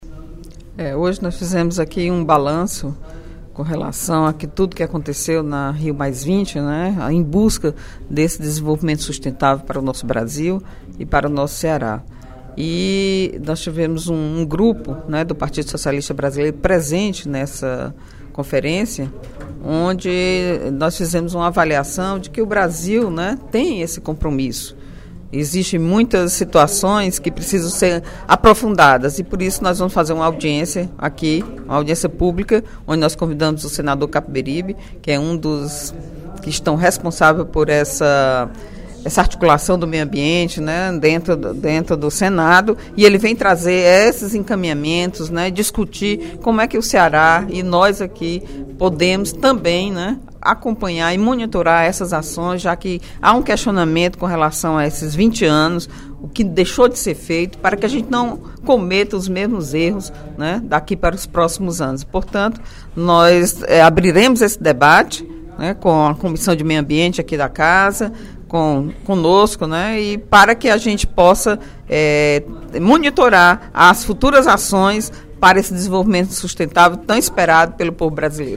A deputada Eliane Novais (PSB) chamou atenção, em pronunciamento na tribuna da Assembleia Legislativa nesta sexta-feira (29/06), para o fato de a Rio+20 ter sido encerrada marcada por um “abismo” entre as expectativas da sociedade civil e o que os governos e seus diplomatas foram capazes de produzir politicamente.